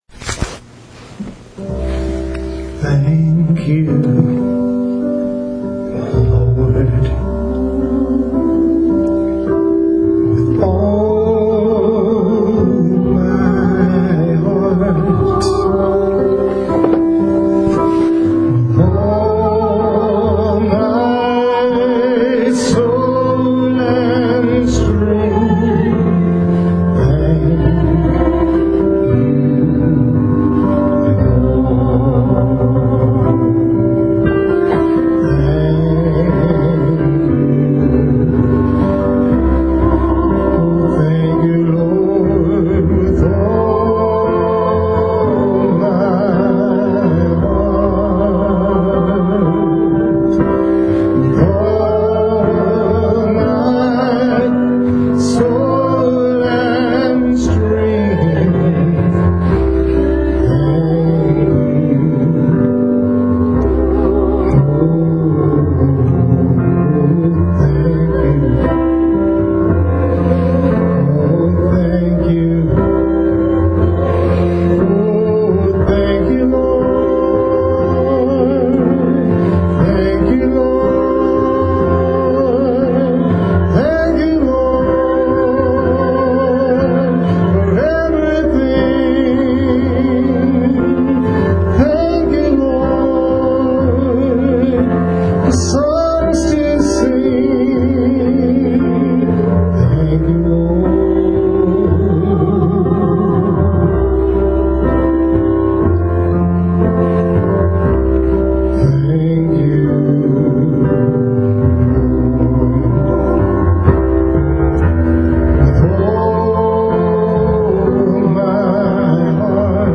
Longer sermons are broken up into smaller...